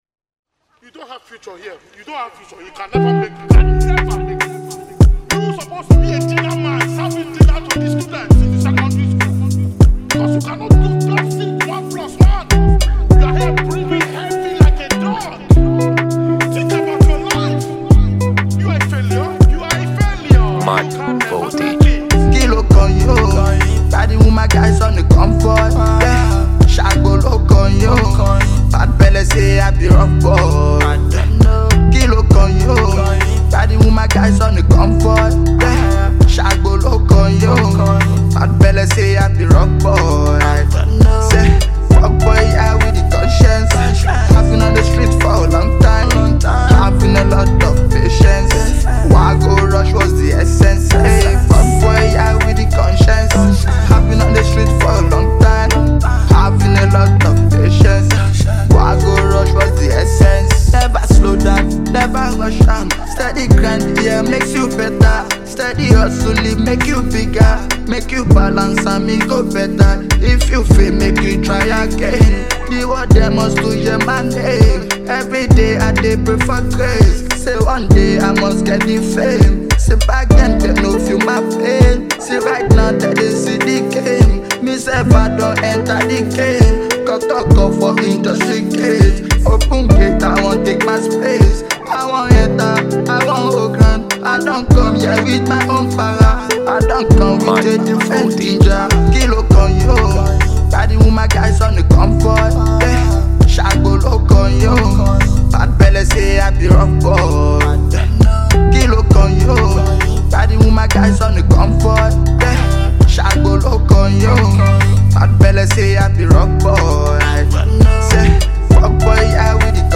and it’s an everyday bop song you won’t get tired of.